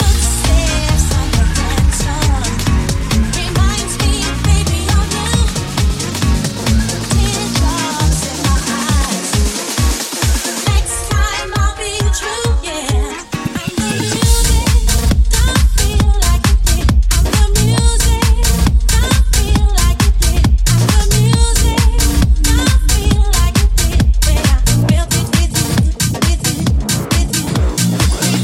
tech house hits
Genere: house,tec house,tecno,remix,hit